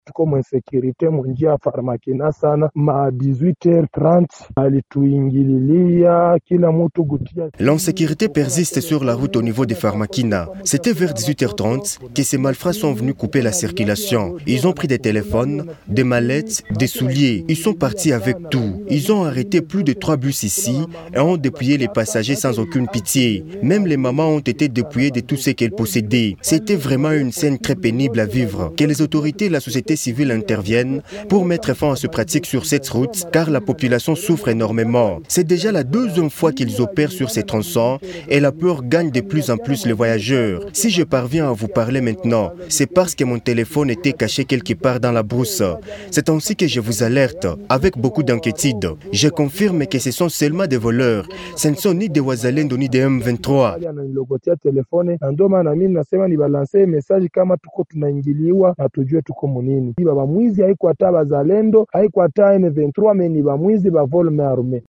Une des victime s’exprime